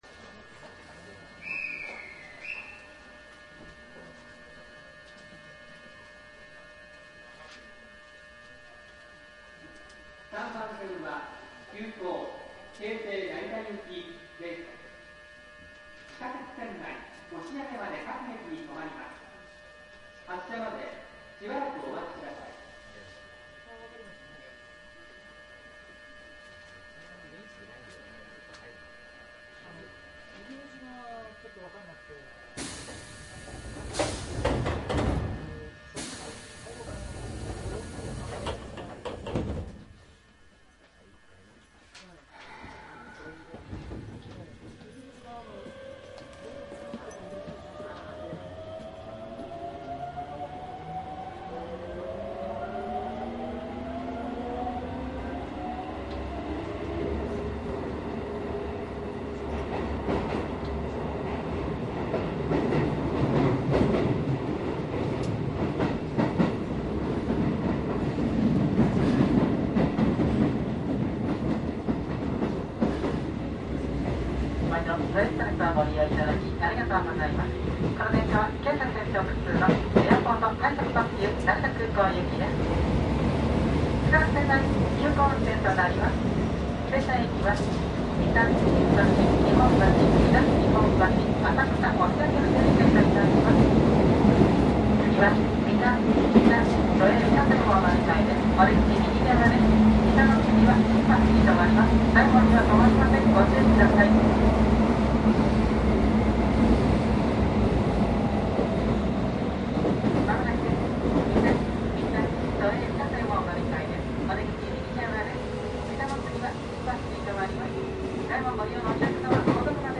走行音は京成3700形で東急1000系に酷似しています。
■【エアポート快特】羽田空港→高砂 3767
マスター音源はデジタル44.1kHz16ビット（マイクＥＣＭ959）で、これを編集ソフトでＣＤに焼いたものです。